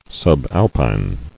[sub·al·pine]